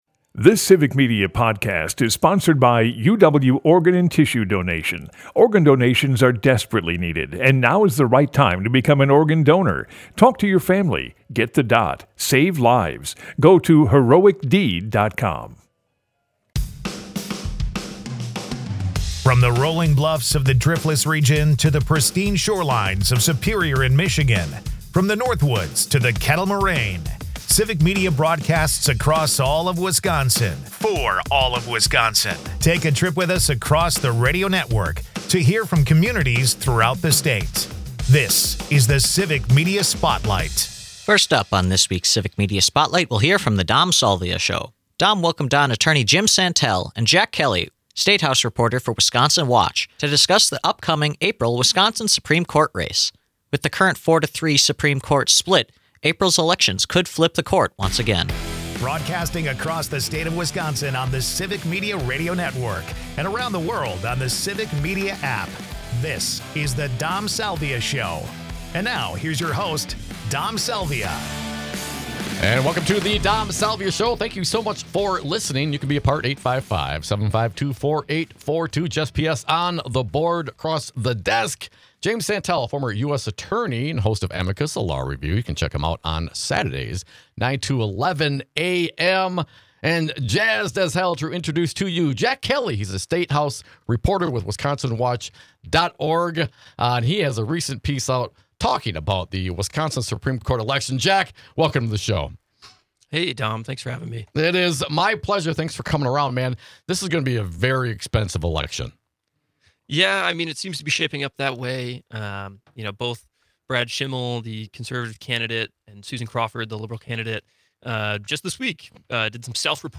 Next, on UpNorthNews Radio, Assembly Minority Leader Rep. Greta Neubauer (D-Racine) and new state Sen. Sarah Keyeski (D-Lodi) discuss the opening of the new session of the Wisconsin Legislature.